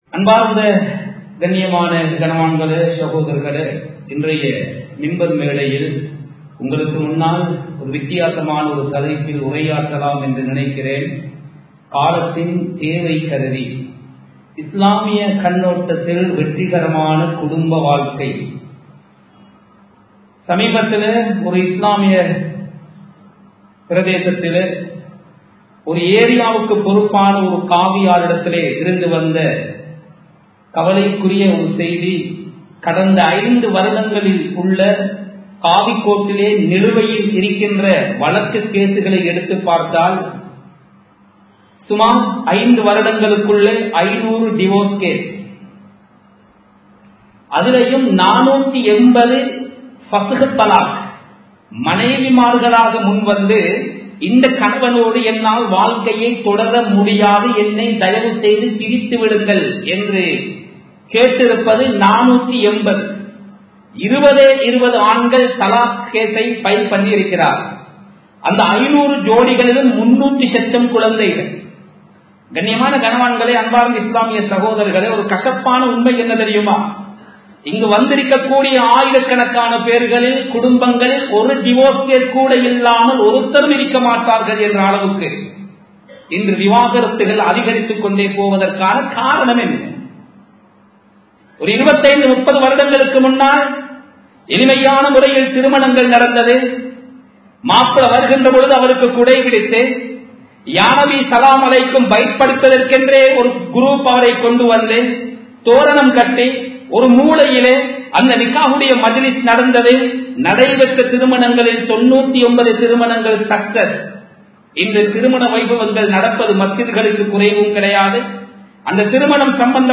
இன்றயை குடும்ப வாழ்வுகளும் சிதைவுகளும் | Audio Bayans | All Ceylon Muslim Youth Community | Addalaichenai
Samman Kottu Jumua Masjith (Red Masjith)